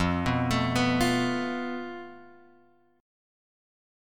F Major Flat 5th